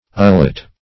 Search Result for " ullet" : The Collaborative International Dictionary of English v.0.48: ullet \ul"let\ ([u^]l"l[e^]t), n. [Cf. OF. hullote, E. howlet.]